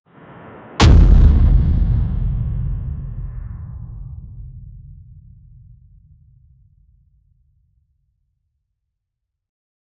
exploding-bazooka-hit-kd6bgvw7.wav